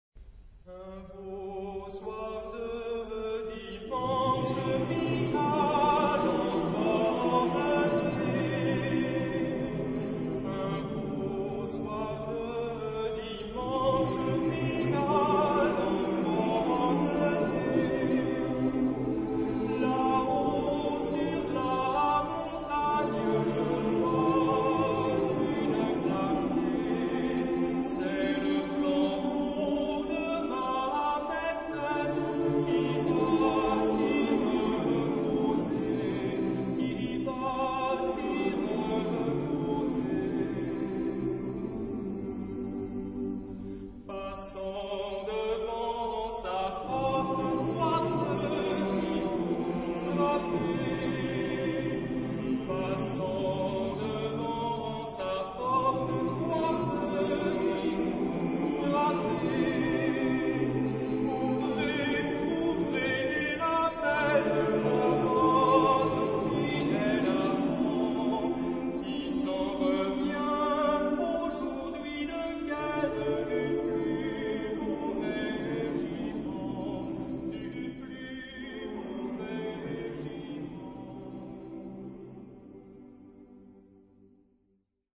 Genre-Stil-Form: Volkslied ; weltlich ; Liedsatz
Charakter des Stückes: einfach
Chorgattung: SATB  (4 gemischter Chor Stimmen )
Solisten: Soprano (1) / Ténor (1)  (2 Solist(en))
Tonart(en): G (tonales Zentrum um)